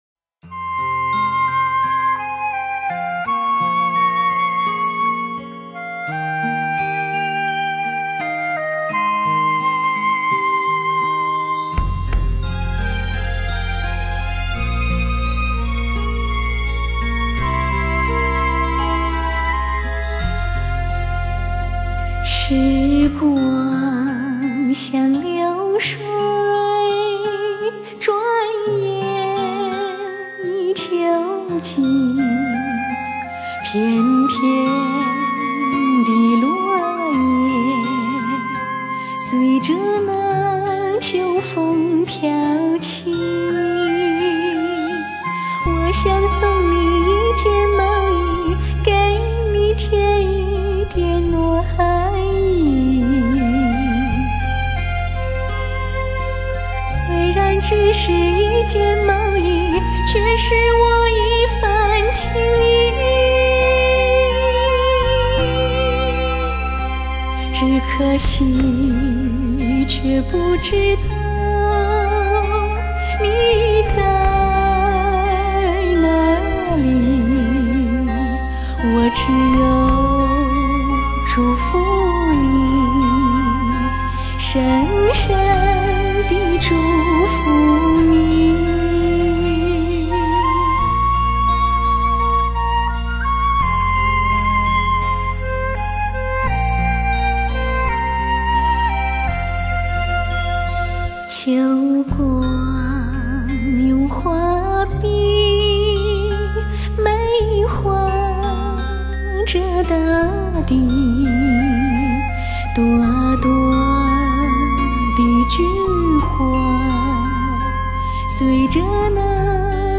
甜甜的少女之声
二胡
竹笛
长笛
口琴
东西乐器巧妙地结合，给你一份清甜，一份细腻，让你远离喧嚣，回归田园。